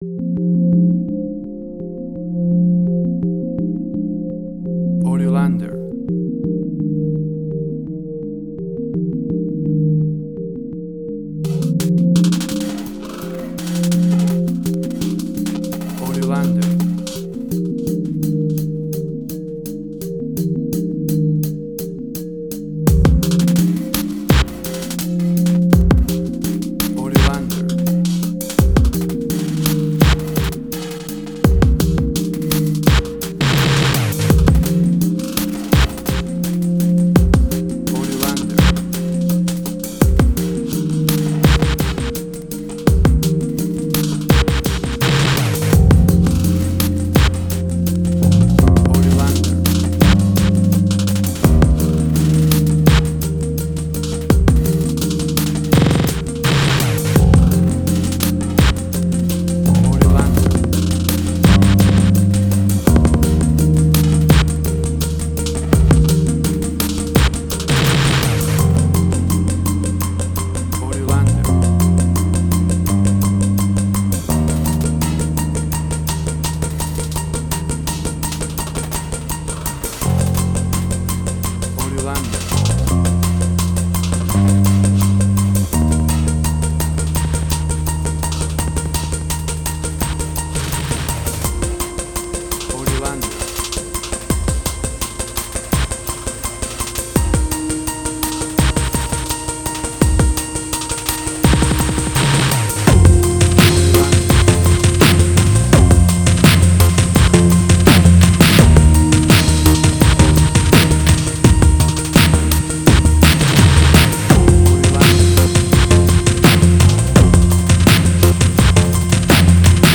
IDM, Glitch.
Tempo (BPM): 84